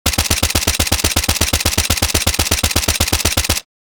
Hundreds of professionally recorded War Sound Effects to download instantly, from Pistols, Missiles and Bombs, Guns, Machine guns and Sniper Rifles!
MK2-assault-rifle-full-auto-fire.mp3